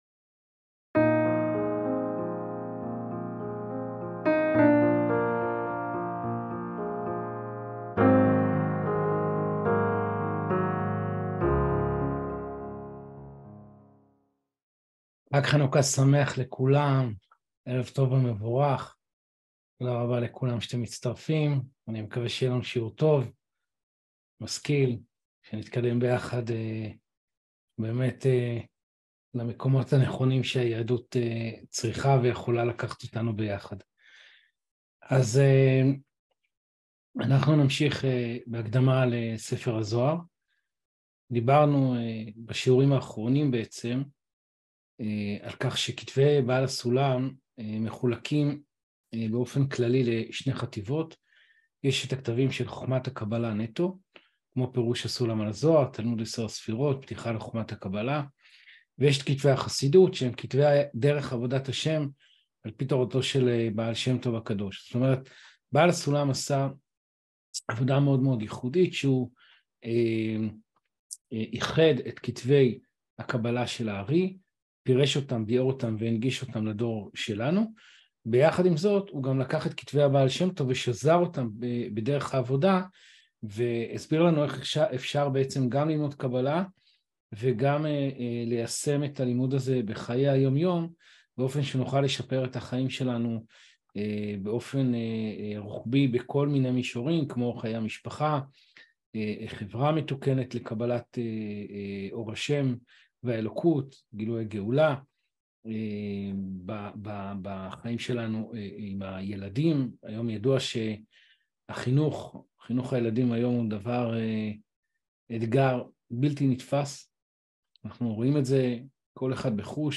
הקדמה לספר הזוהר 9 | שידור חי מהזום